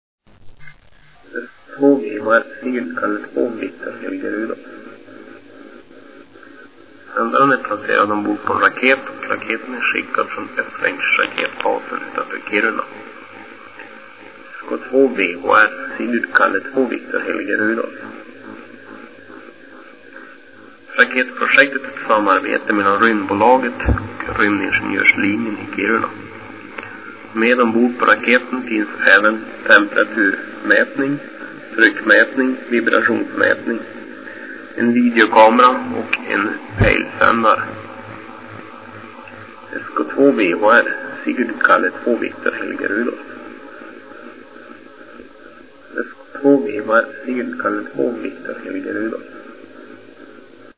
Så här lyder den text som från en lagrad ljudfil lästes upp via sändaren ombord: